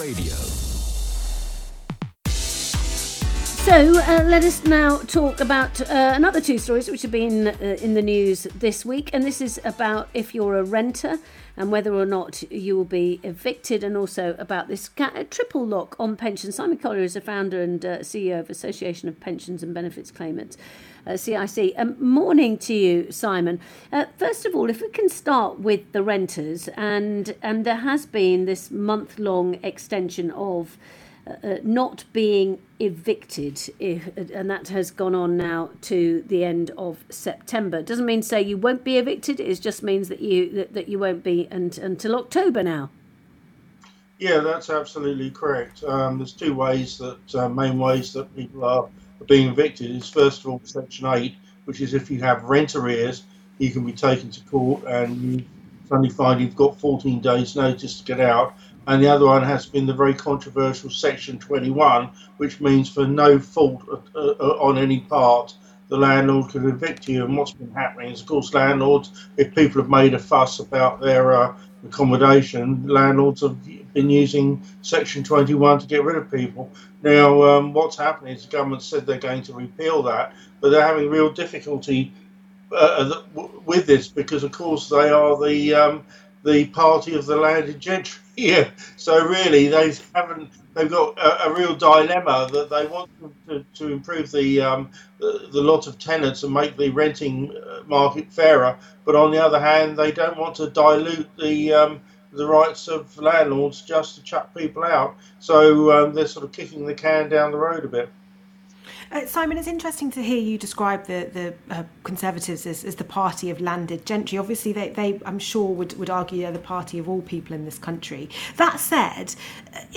RADIO APPEARANCE